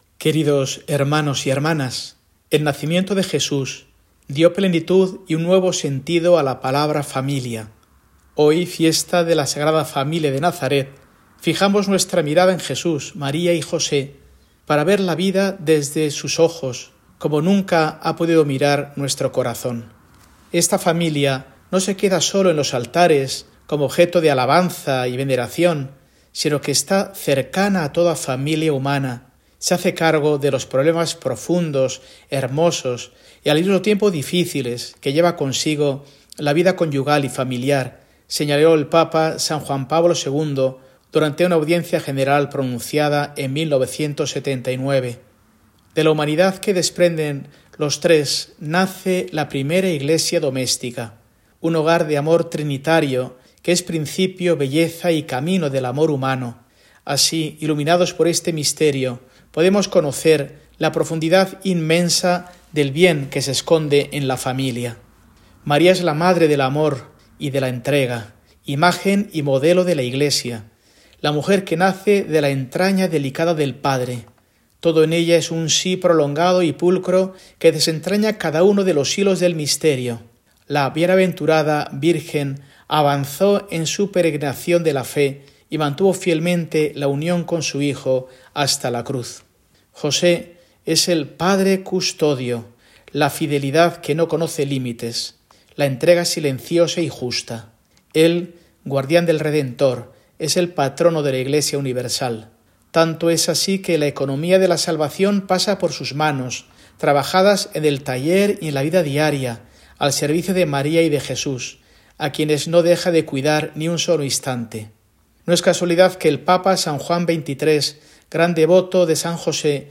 Mensaje de Mons. Mario Iceta Gavicagogeascoa, arzobispo de Burgos, para el domingo, 29 de diciembre de 2024, apertura diocesana del Jubileo 2025